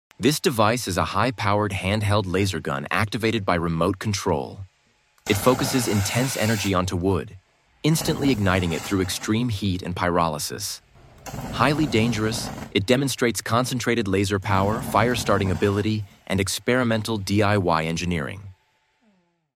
A Powerful DIY Handheld Laser Sound Effects Free Download